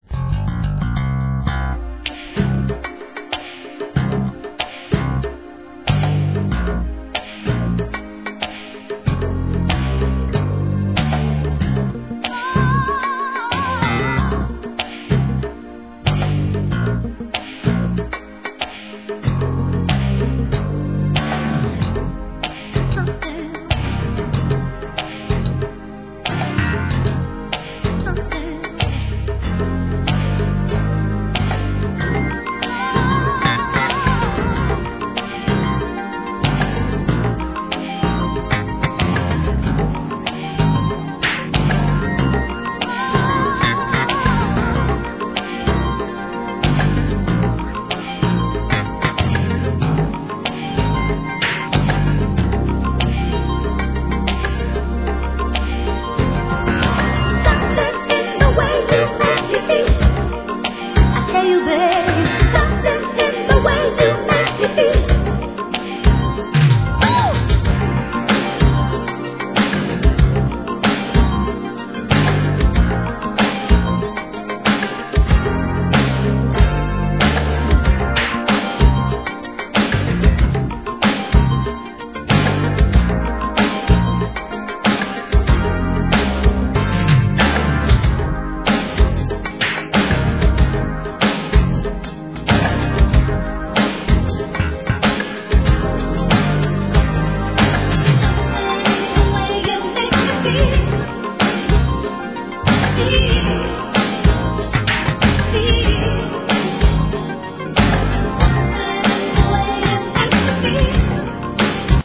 HIP HOP/R&B
素晴らしいミディアム・ヴォーカル物!